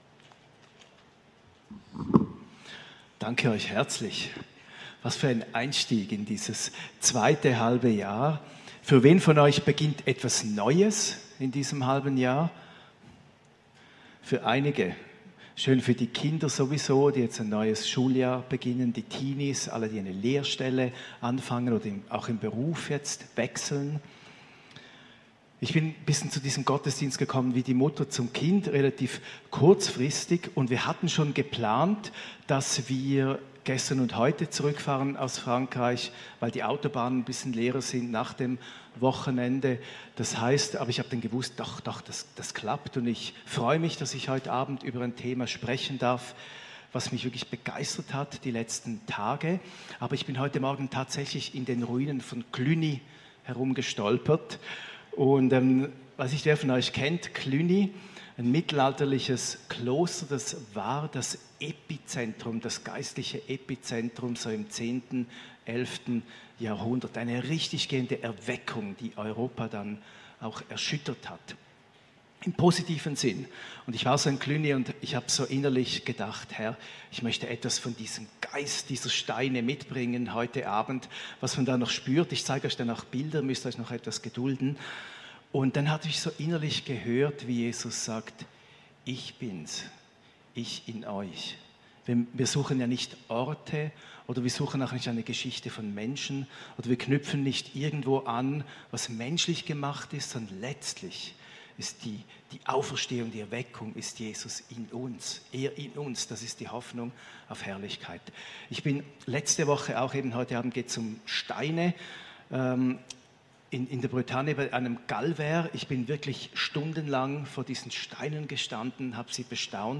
Dienstagsgottesdienst vom 12.